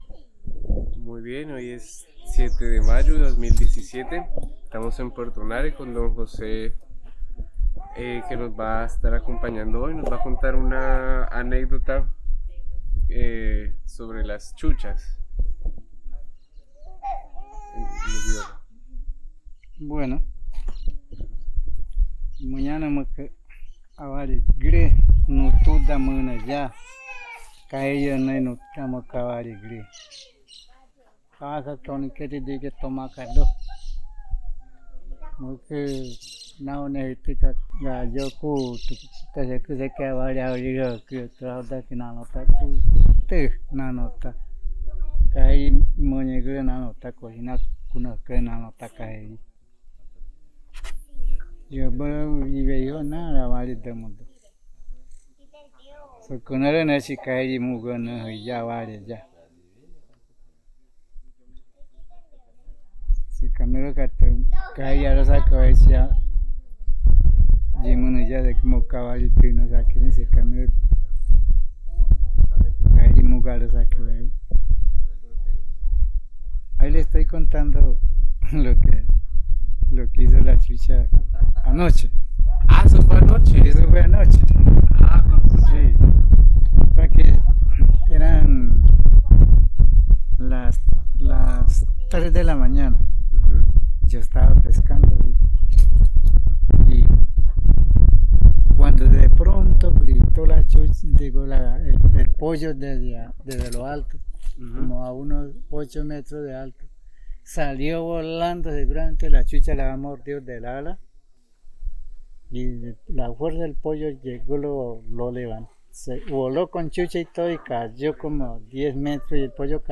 Anécdota de la chucha
Puerto Nare, Guaviare